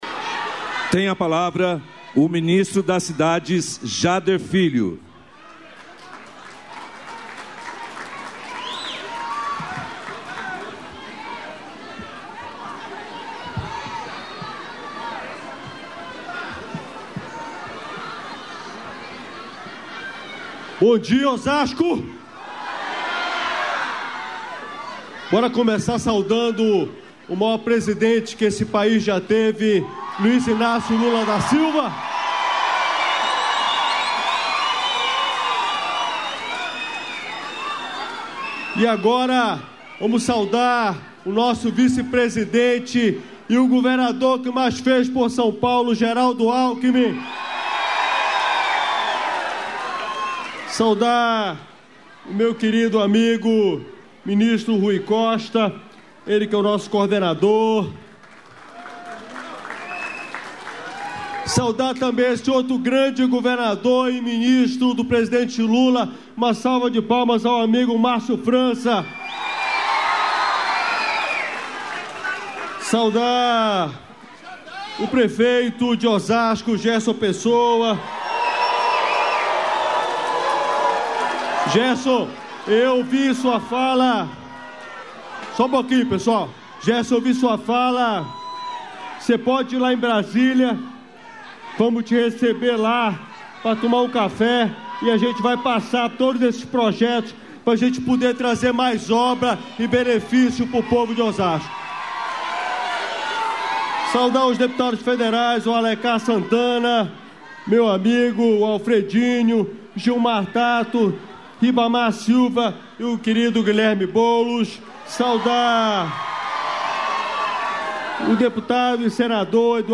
Ministros discursam em cerimônia no Vale do Jequitinhonha (MG), pela educação nos quilombos
Íntegra dos discursos da ministra da Igualdade Racial, Anielle Franco; dos Direitos Humanos e Cidadania, Macaé Evaristo; dos Povos Indígenas, Soia Guajajara; da Educação, Camilo Santana e de Minas e Energia, Alexandre Silveira, na cerimônia de entregas do governo federal no Vale do Jequitinhonha (MG), nesta quinta-feira (24), como parte do I Encontro Regional de Educação Escolar Quilombola do Sudeste.